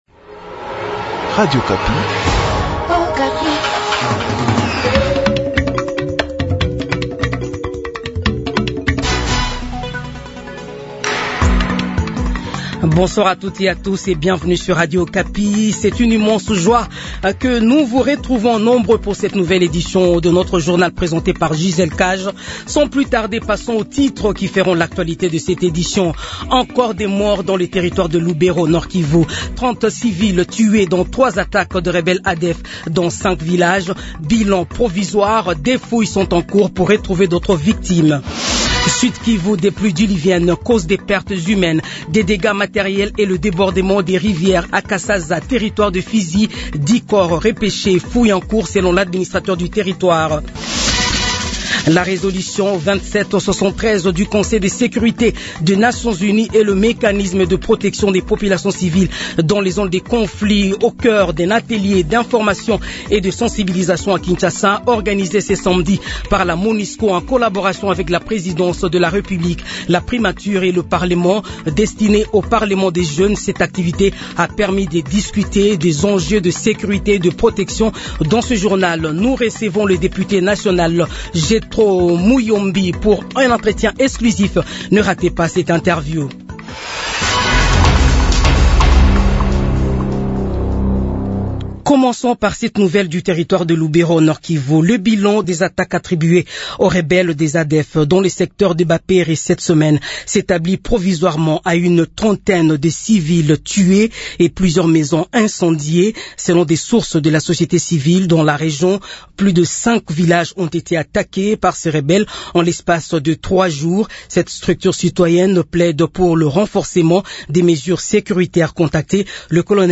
Journal 18h